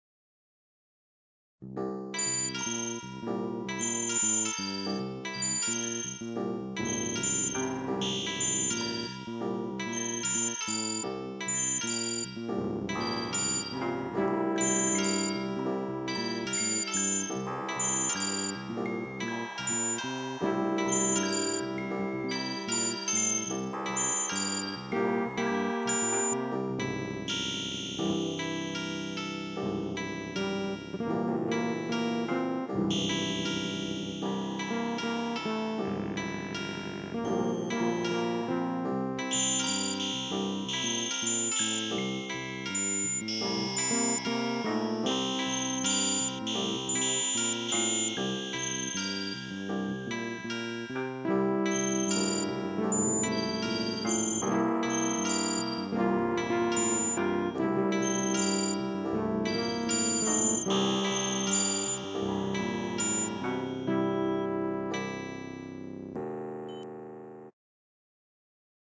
Weird, full of bizzare sounds